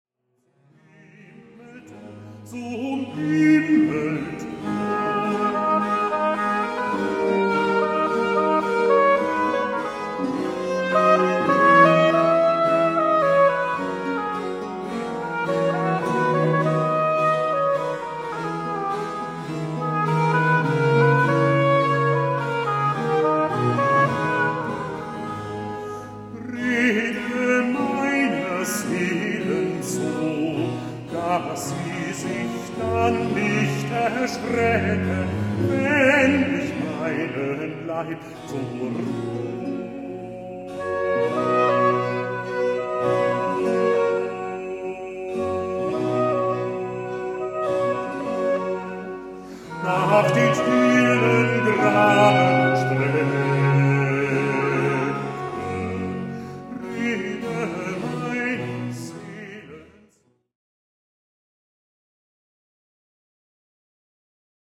Georg Philipp Telemann: „Jesu komme mir entgegen“ aus Kantate „Ach Herr! Lehr uns bedenken wohl“ TWV 1:24 (Live-Mitschnitt des Konzerts vom 16.07.2017 in Benediktbeuern, Bass: Klaus Mertens)